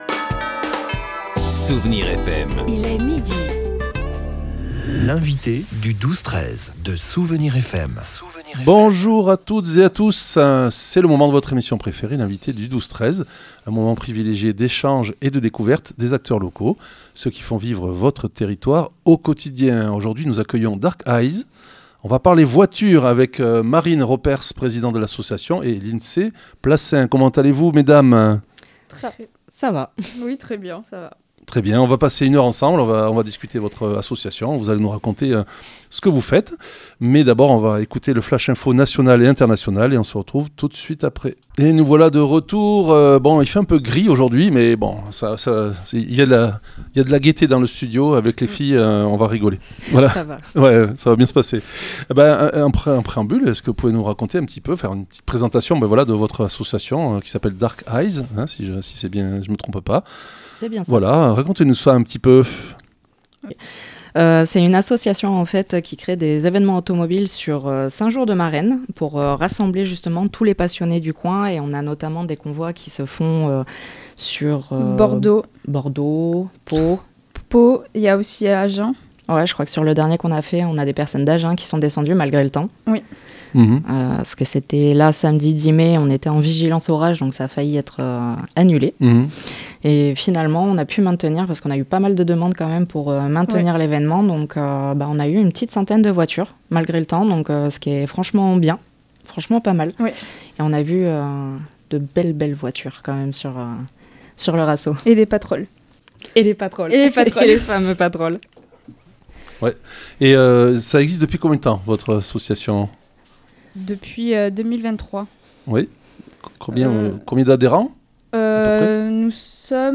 L'invité(e) du 12-13 de Soustons recevait aujourd'hui l'association DARK EYES.